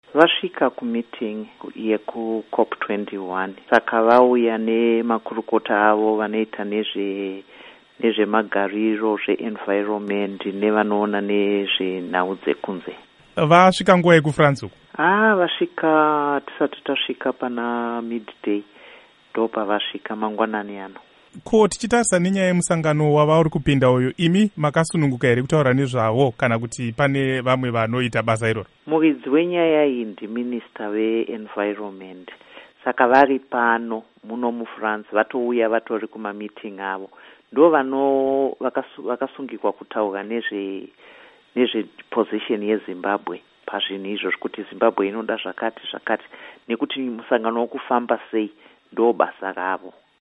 Hurukuro naAmai Rudo Chitiga